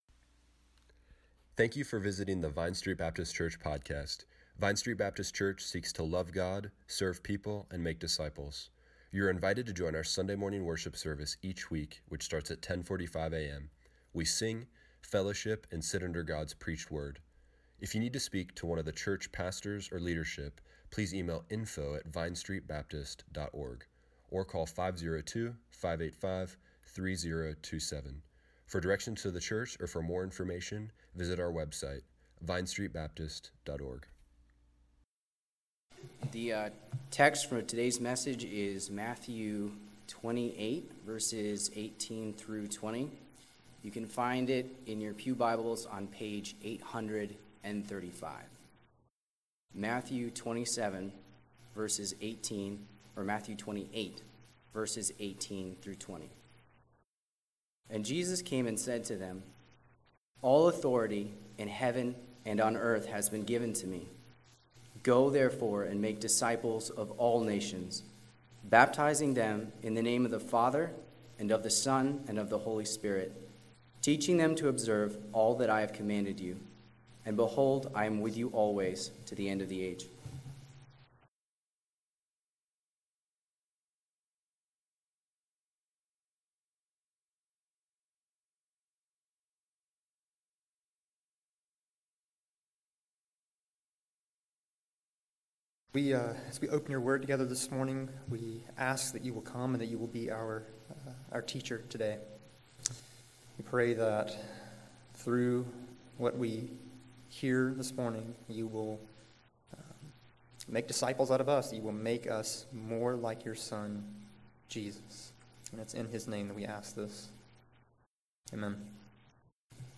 September 24, 2017 Morning Worship | Vine Street Baptist Church